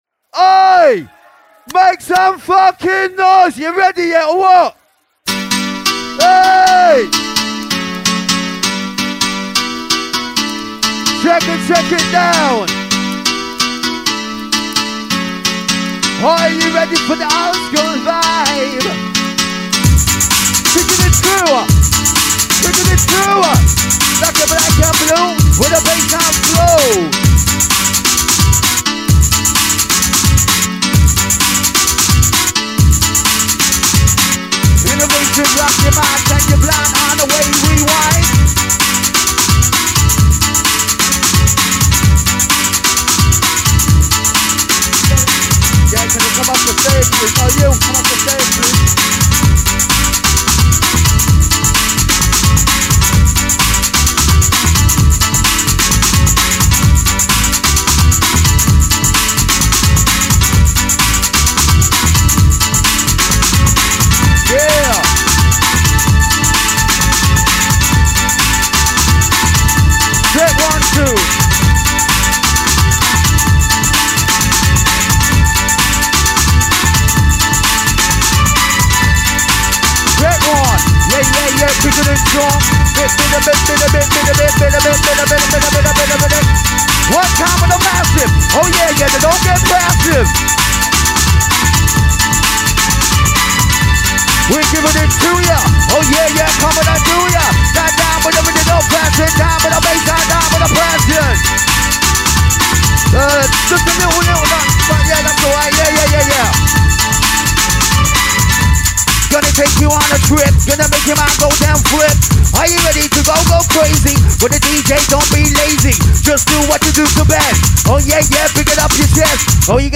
OLD SKOOL / HARDCORE BREAKS / ACID HOUSE